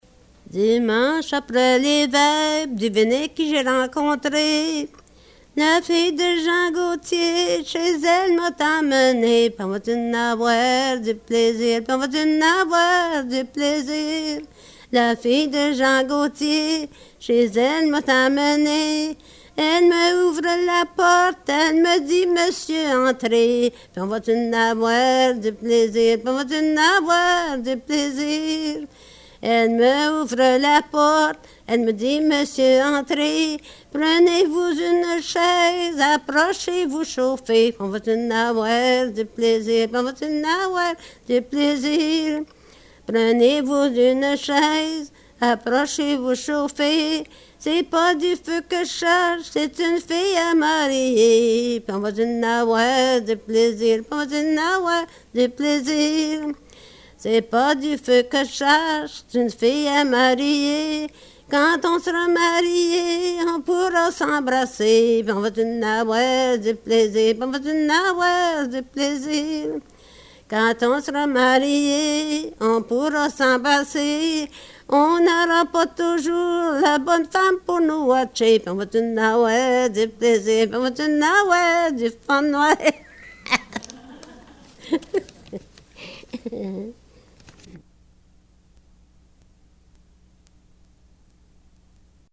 Folk Songs, French--New England
Excerpt from interview
sound cassette (analog)